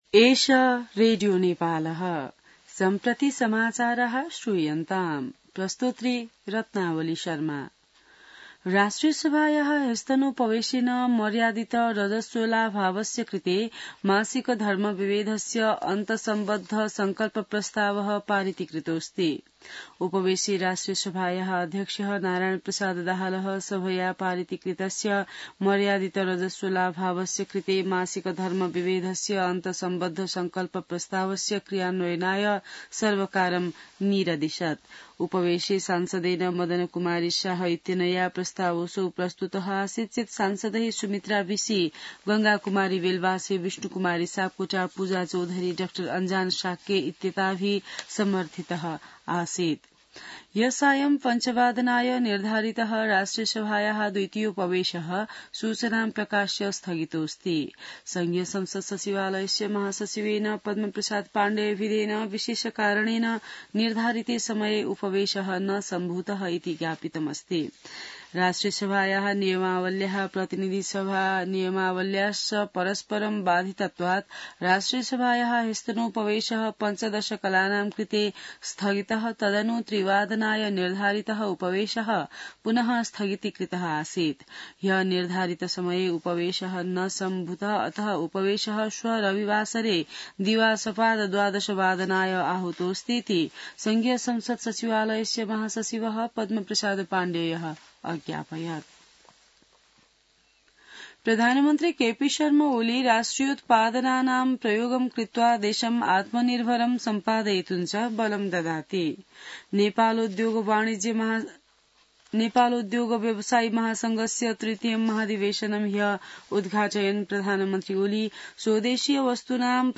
संस्कृत समाचार : ९ चैत , २०८१